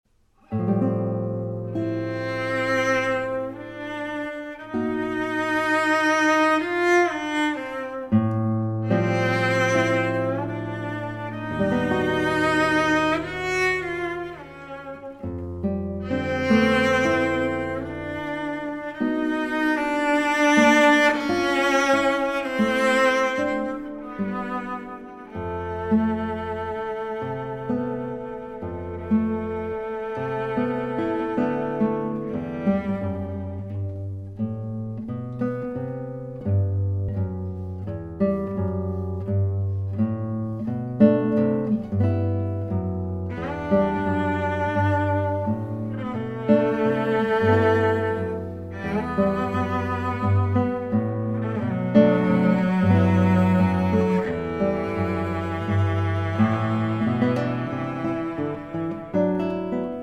for Cello and Guitar